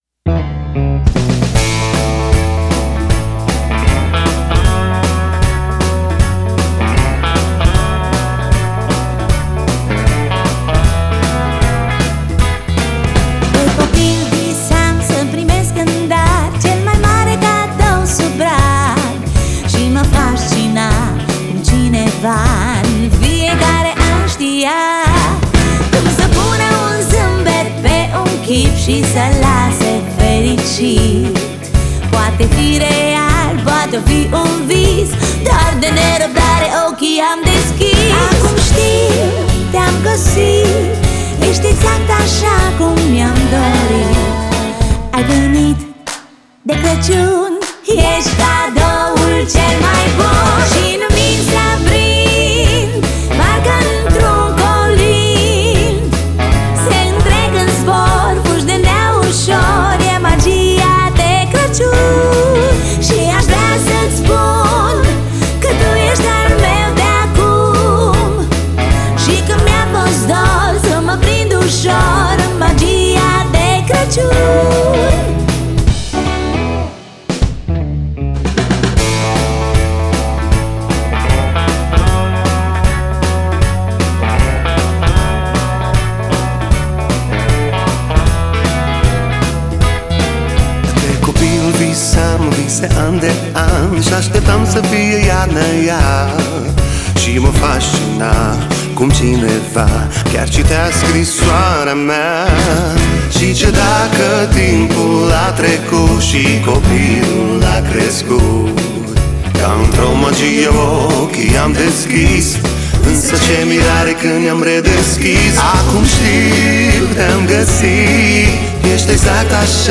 это яркая и мелодичная рождественская песня в жанре поп